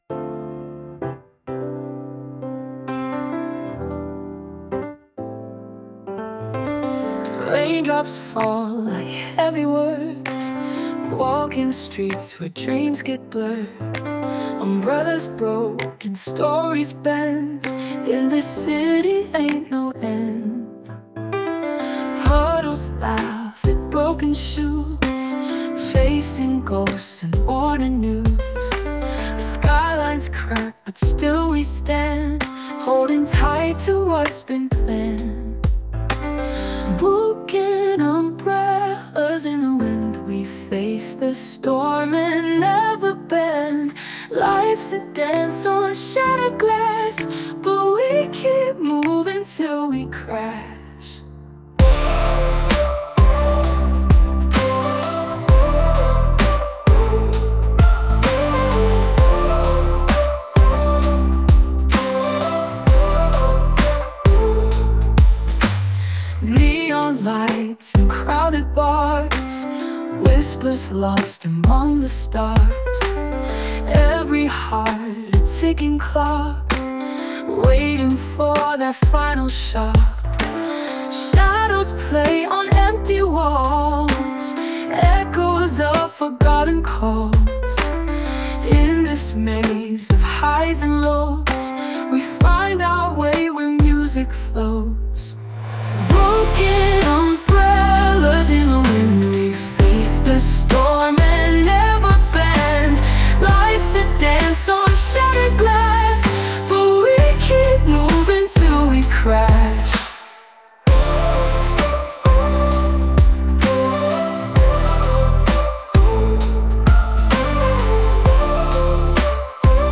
"Broken Umbrellas" (pop/R&B)